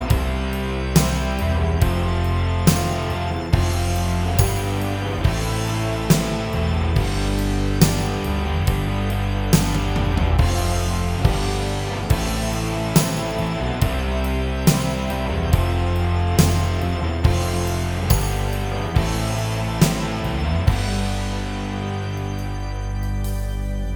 Minus Solo And Stereo Rhythm Rock 4:11 Buy £1.50